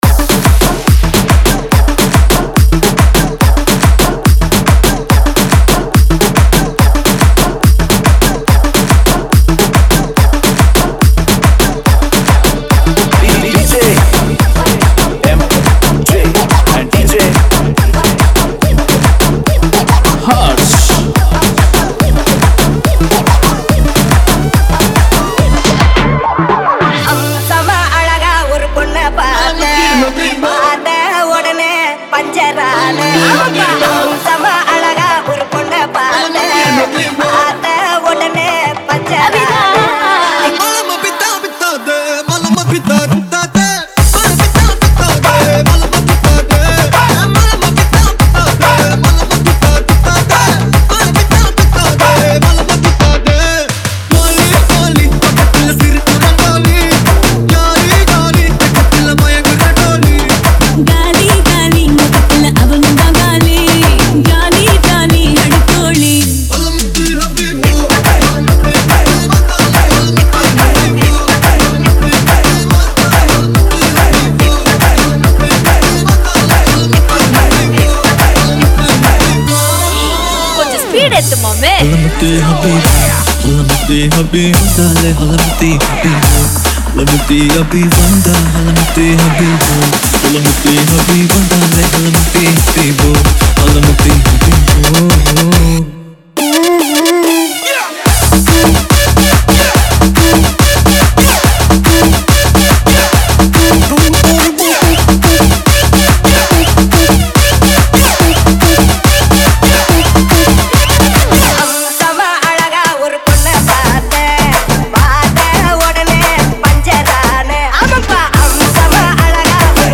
Telug Dj Collection 2022 Songs Download